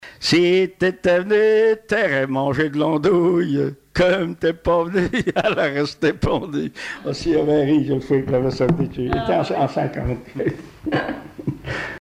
Chants brefs
Témoignages et chansons traditionnelles et populaires
Pièce musicale inédite